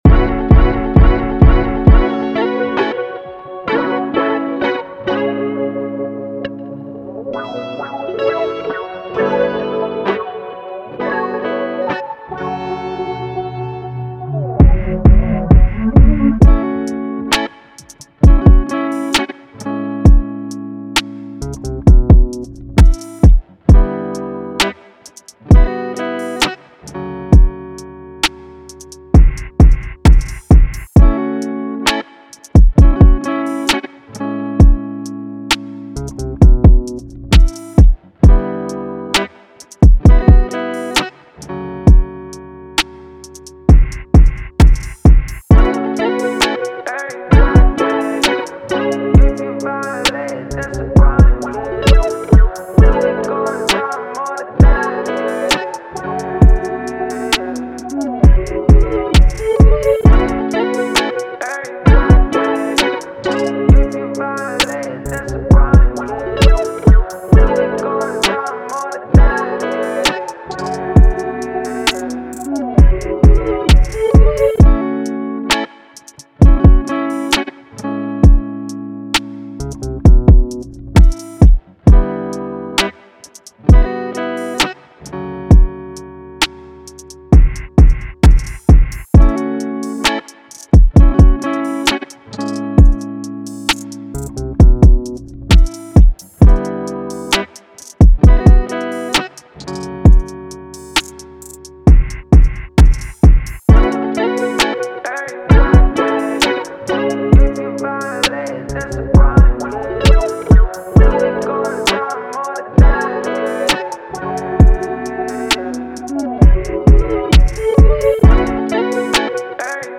R&B, Soul
Amaj